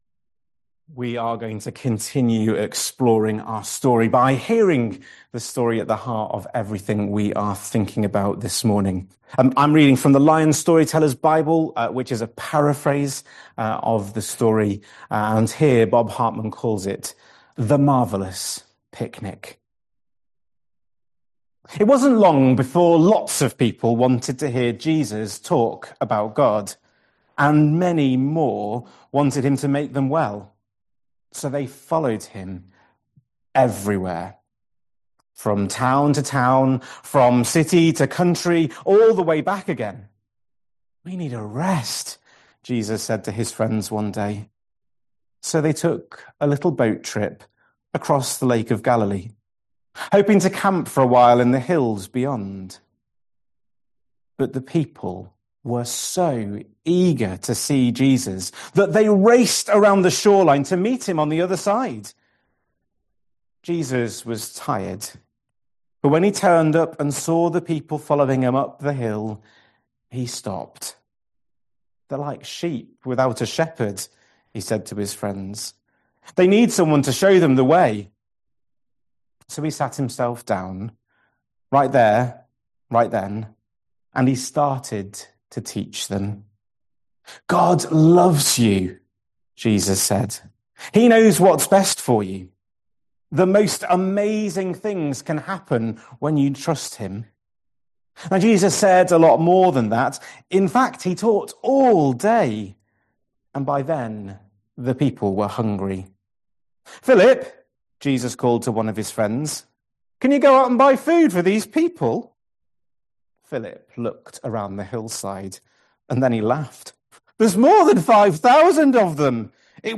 7th September 2025 Sunday Reading and Talk - St Luke's